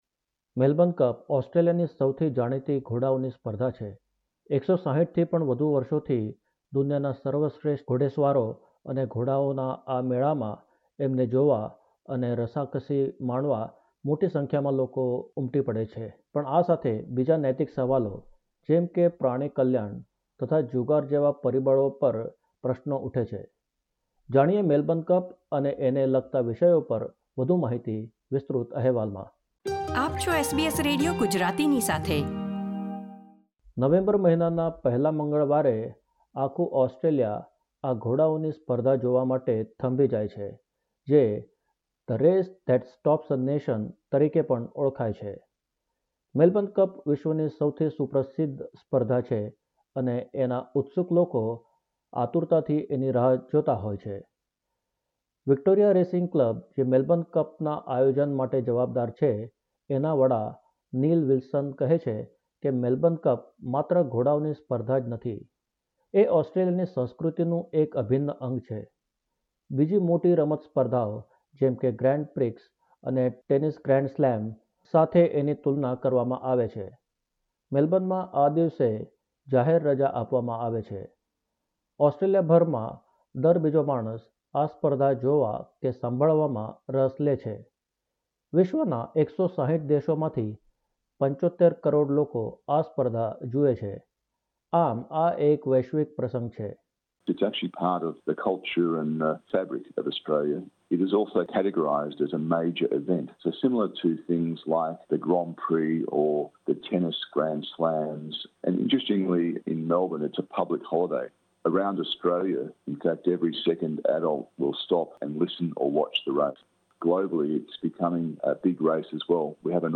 વધુ વિગતો મેળવીએ અહેવાલમાં.